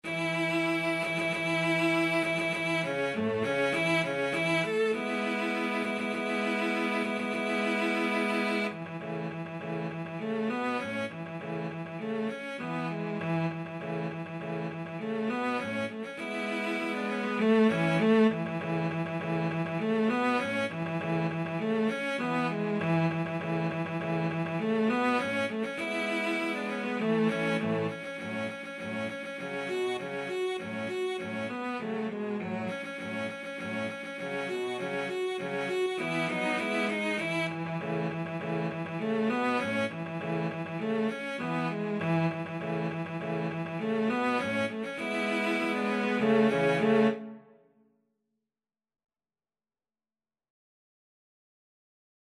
Free Sheet music for Cello Trio
A major (Sounding Pitch) (View more A major Music for Cello Trio )
Presto =200 (View more music marked Presto)
4/4 (View more 4/4 Music)
Classical (View more Classical Cello Trio Music)